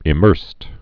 (ĭ-mûrst)